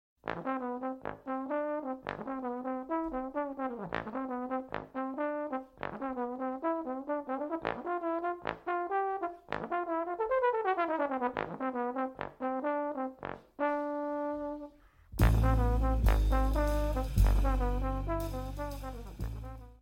TROMBÓN (viento metal)